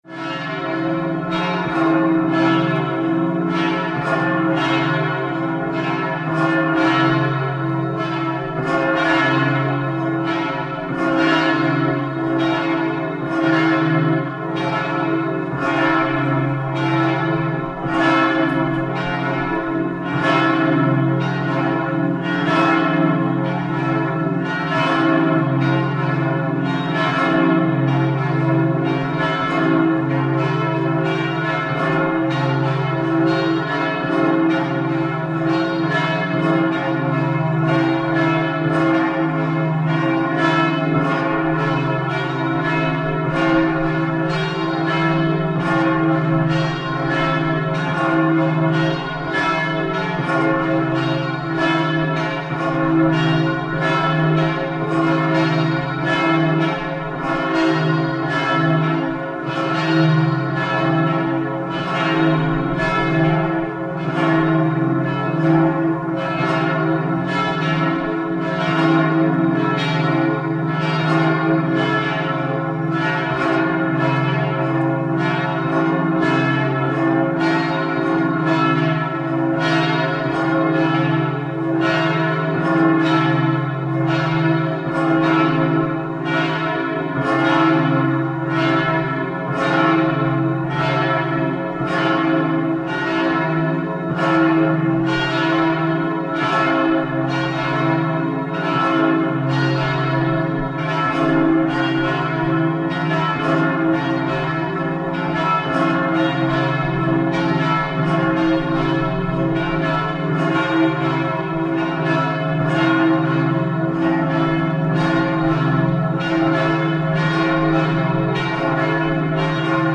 für vier vierstimmige Chöre (1861)